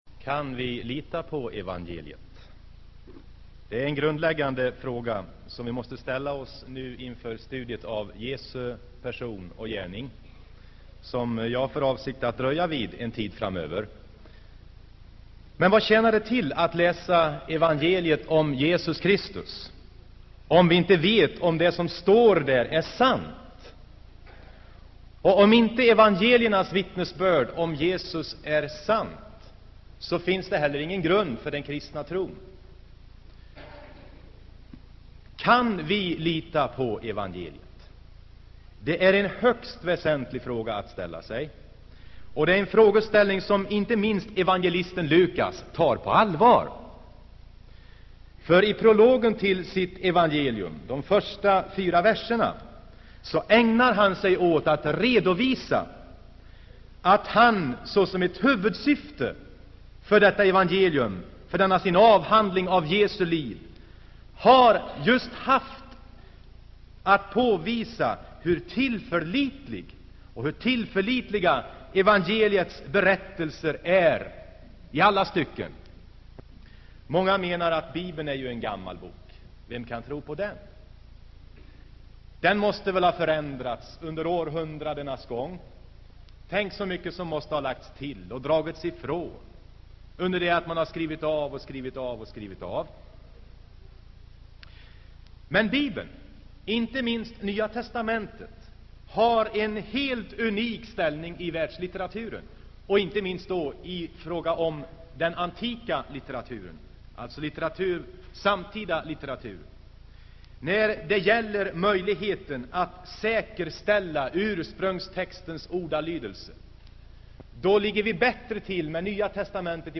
Inspelad i Saronkyrkan, Göteborg 1980-11-16.